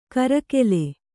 ♪ karakele